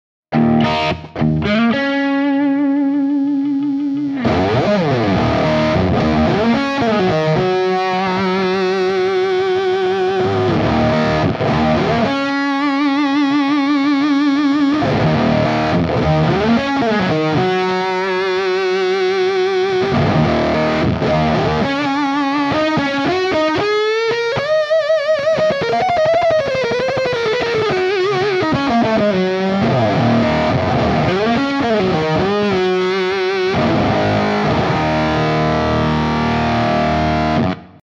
Afros Fuss (just fuzz)
Afros-Fuzz.wav-just-fuzz.mp3